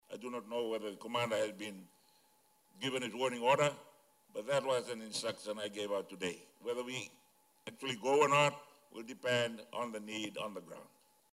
Speaking at the opening of the 2023 Chiefs of Defence Conference in Natadola last night, Rabuka conveyed Fiji’s solidarity with Hawaii and its willingness to aid in the ongoing relief operations.